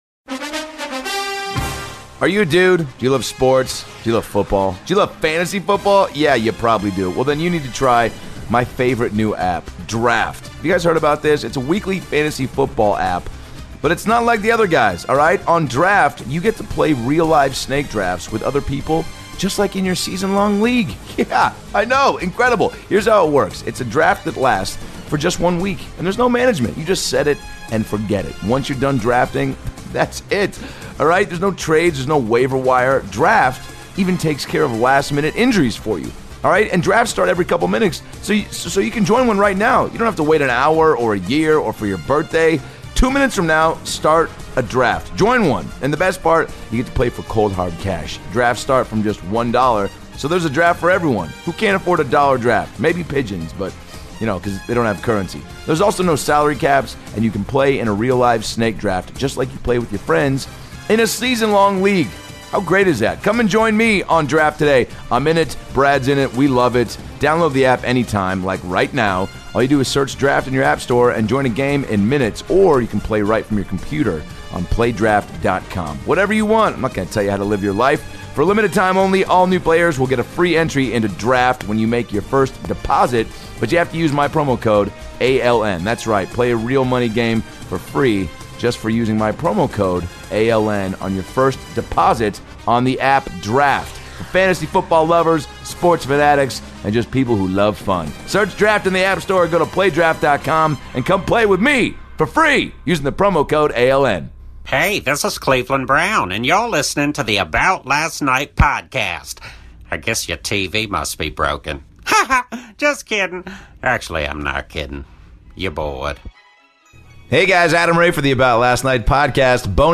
Sorry ladies, but Brad Williams is officially off the market! Adam calls Brad the day after his wedding for a FULL wedding recap (his wife also makes an appearance on the pod)!